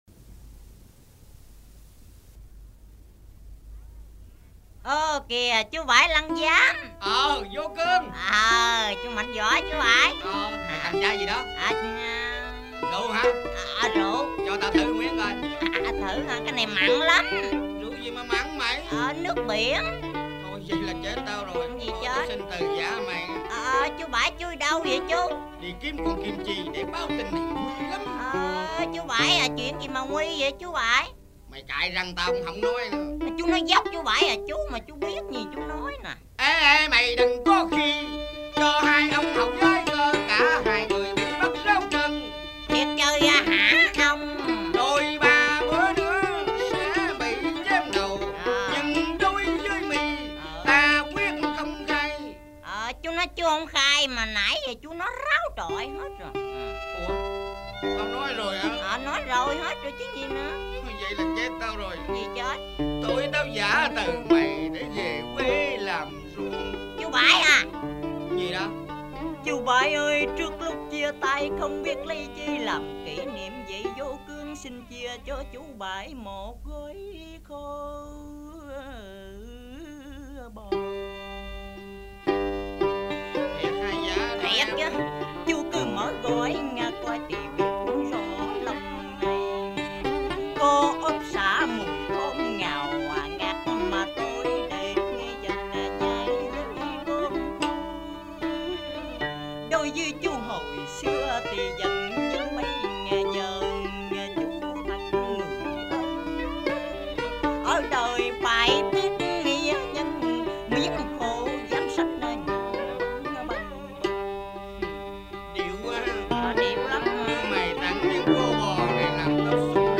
Thể loại: Cải Lương